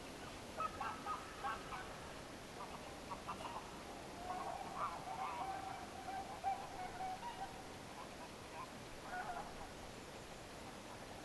kotihanhen ääniä
kotihanhen_ilm_kotihanhikoiraan_aania.mp3